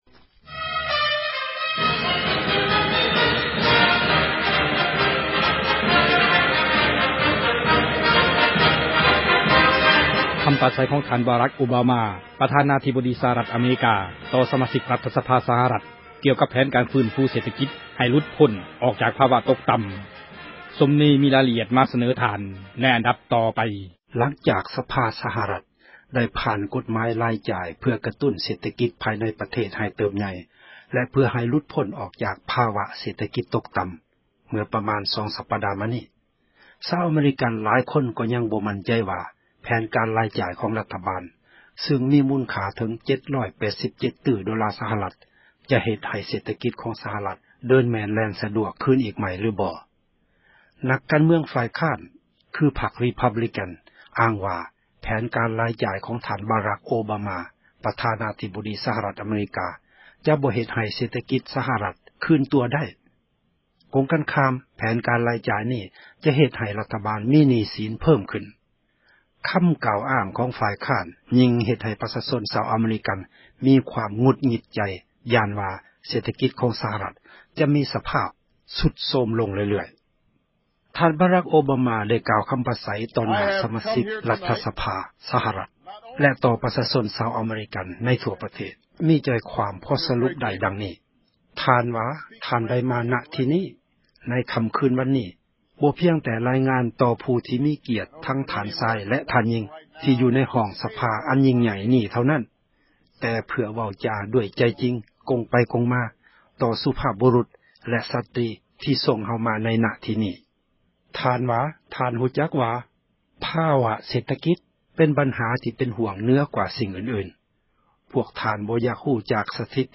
ຄໍາປາໃສຂອງ Obama ປະທານາທິບໍດີ ຕໍ່ສະມາຊິກ ຣັຖະສະພາ